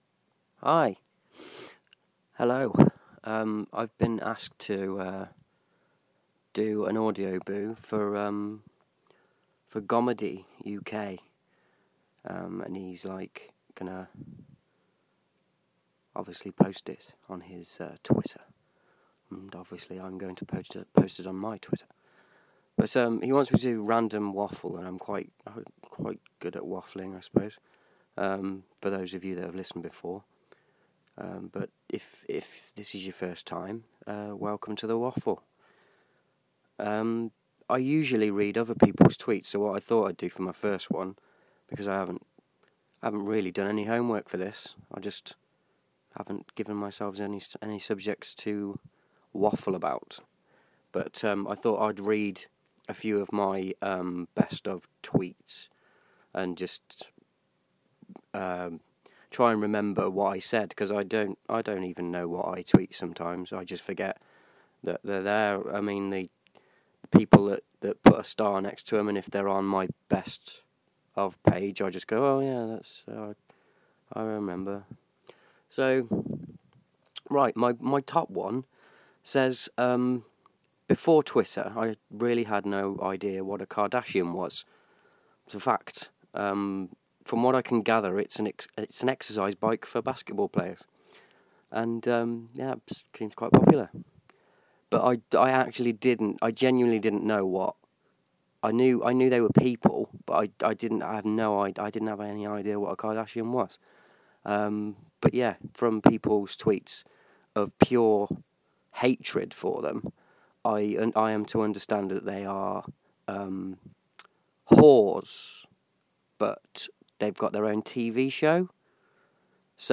It's me, reading my own tweets.